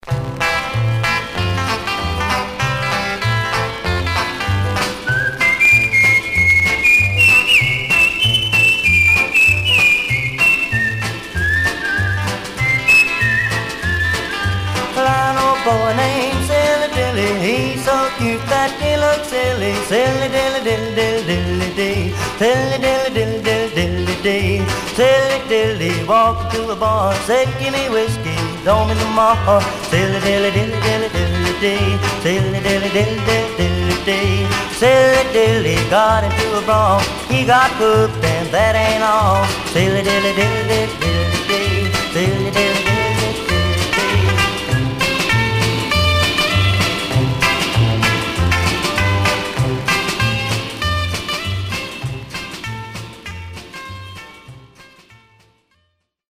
Much surface noise/wear
Mono
Teen